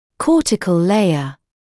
[‘kɔːtɪkl ‘leɪə][‘коːтикл ‘лэйэ]кортикальный слой